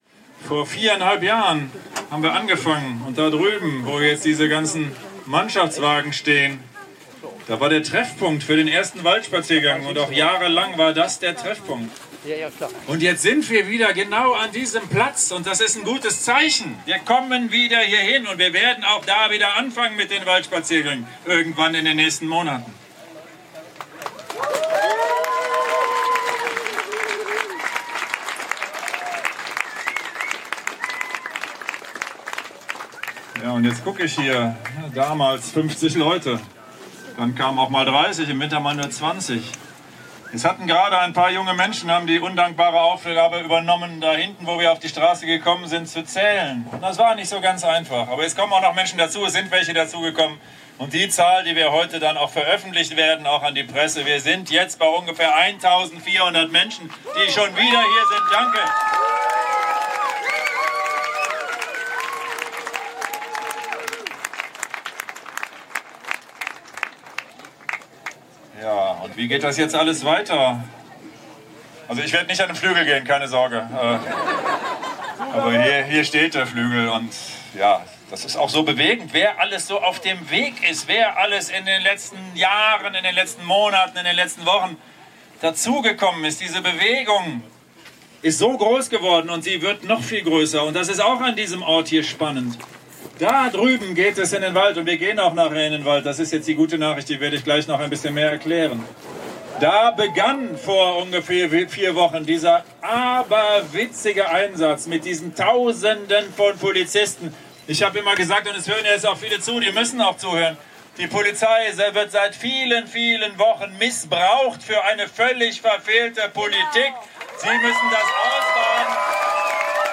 Nach der gestrigen bewegenden Massen-Demonstration ging es heute bei der Waldführung wieder etwas beschaulicher zu.